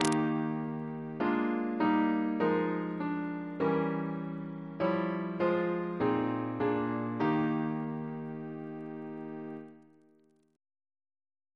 Single chant in E♭ Composer: Raymond Lewis Reference psalters: CWP: 234